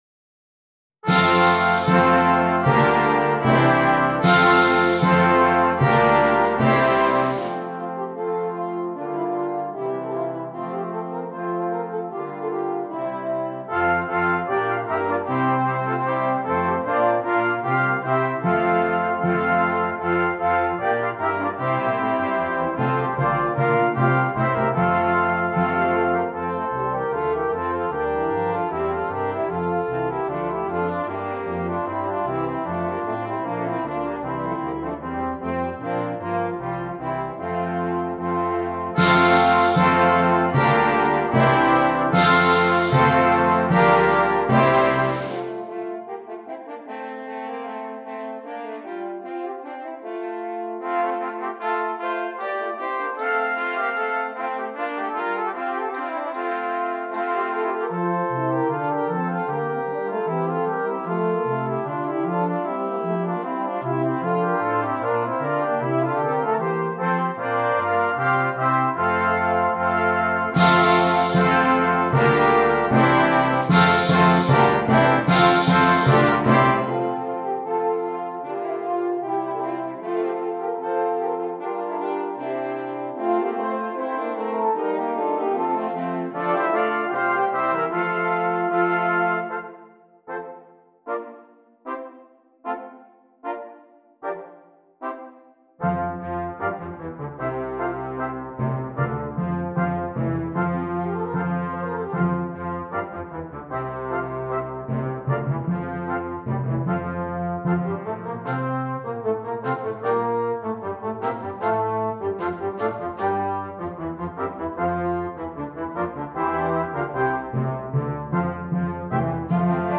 Brass Choir
Traditional Carol